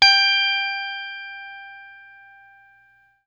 FENDRPLUCKAQ.wav